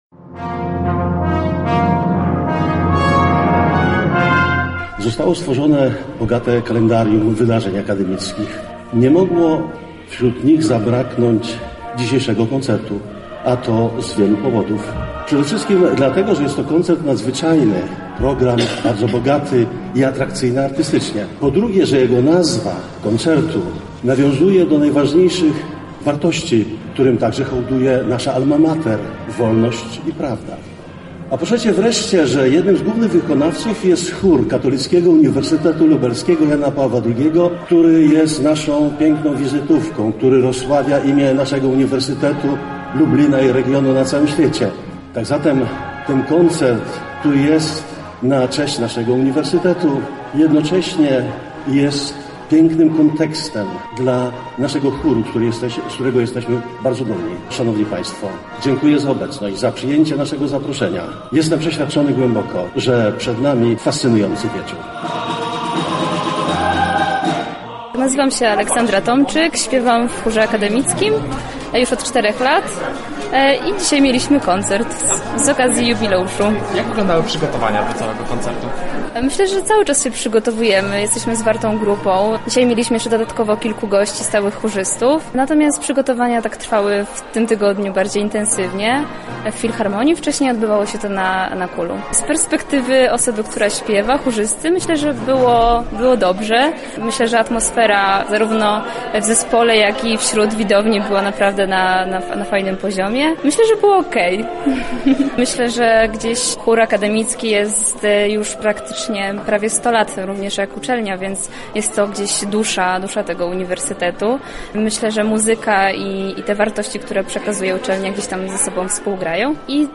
Tym razem wczoraj w sali koncertowej Filharmonii Lubelskiej odbył się koncert „W służbie wolności i prawdzie”.
Relacja